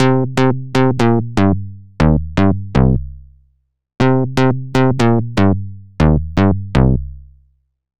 Ew Bass.wav